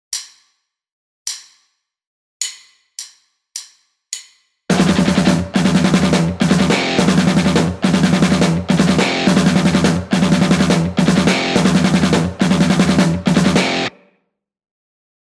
Rhythm riffs modelled on the masters
This down-picked riff ramps up the speed to a whopping 180bpm and also includes some fast fret-hand chord slides.